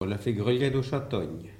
Notre-Dame-de-Monts
Locutions vernaculaires